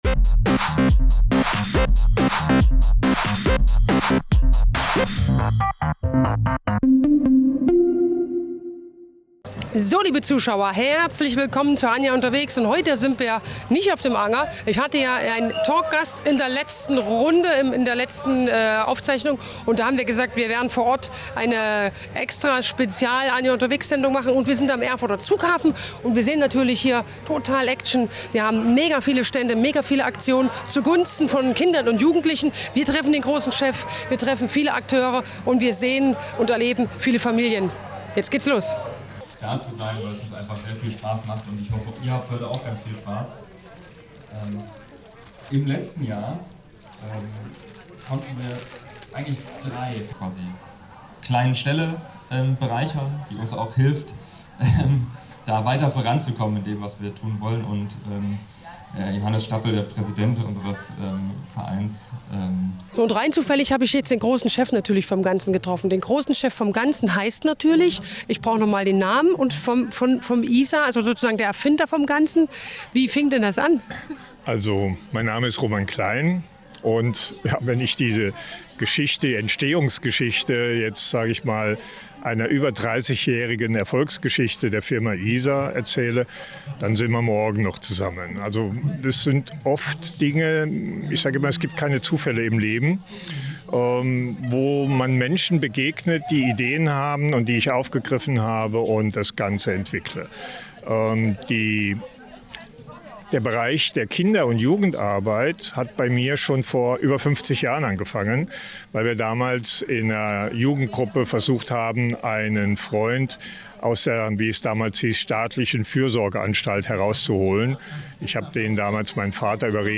Bunte St�nde, fr�hliche Musik und ein Meer aus Menschen: Der Apoldaer Zwiebelmarkt lockte wieder mit regionalen Spezialit�ten, kreativen Angeboten und lebhaften Tanzauff�hrungen.
Der Beitrag zeigt einige Impressionen vom turbulenten Marktgeschehen.